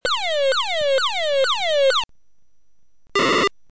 Ciò che ascoltate è il suono di un emittente di soccorso su 121.500 Mhz seguita da un altra emittente su 406 Mhz.